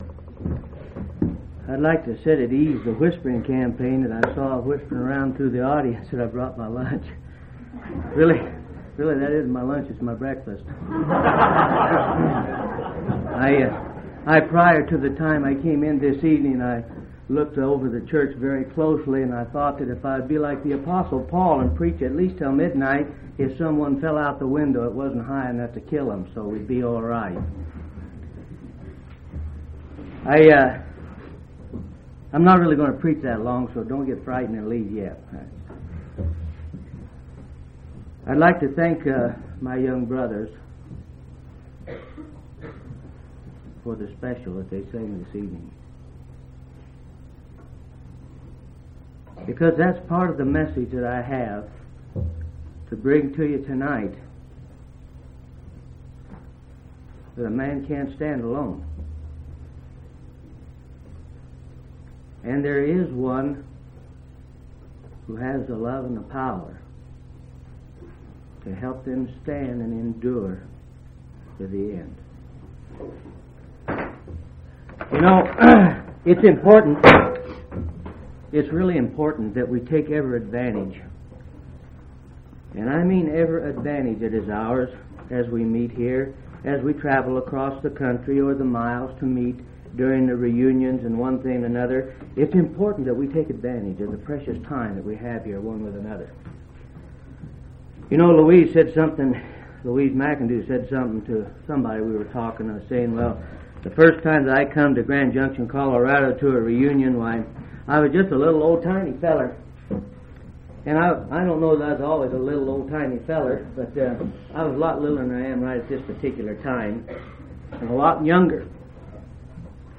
6/11/1983 Location: Colorado Reunion Event